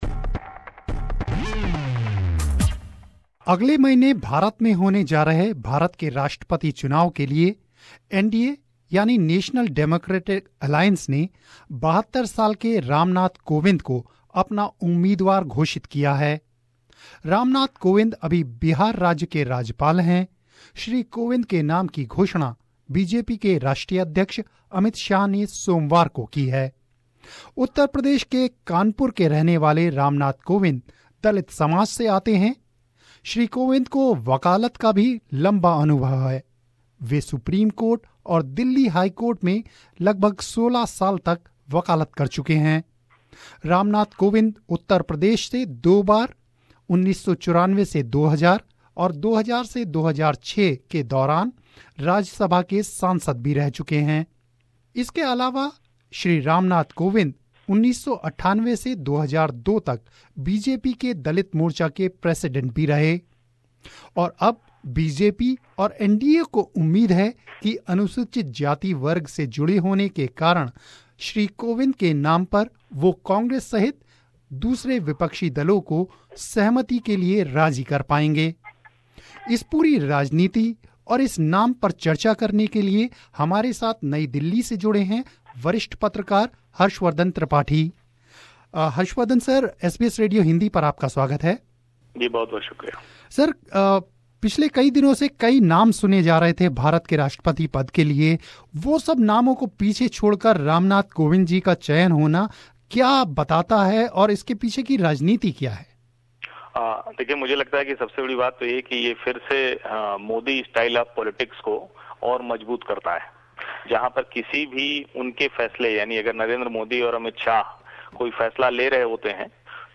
National Democratic Alliance (NDA) of India has declared Ram Nath Kovind's name as its Presidential candidate on Monday. Senior Journalist